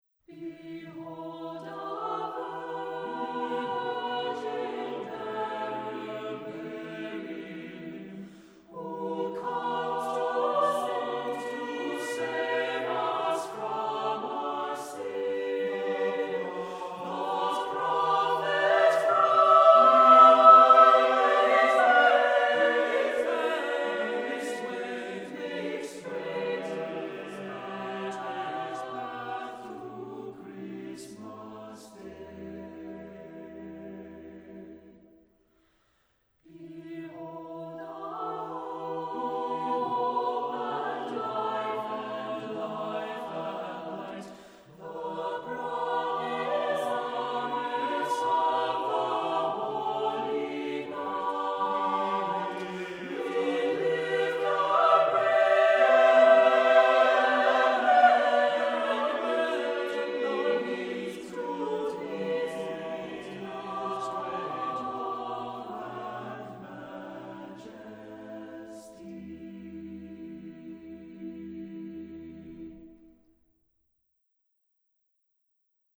SATB (4 mixed voices )
Tonality: E minor